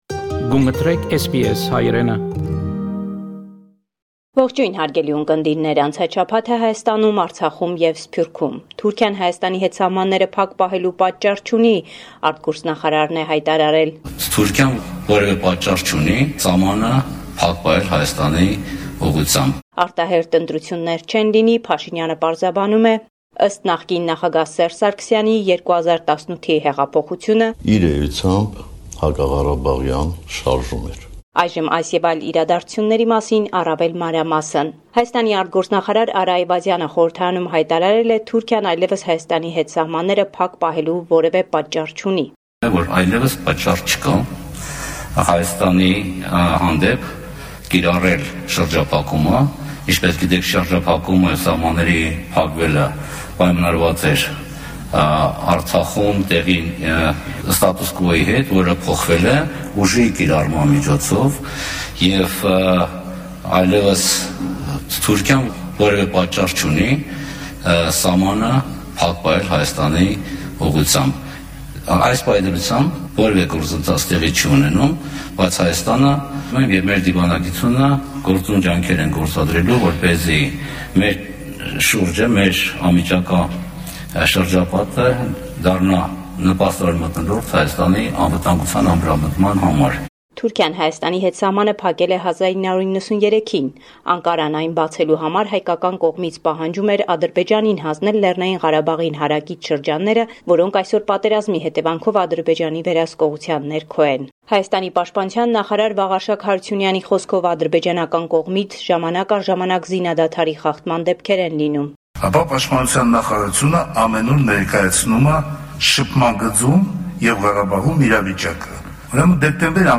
News from Armenia, Artsakh and the Diaspora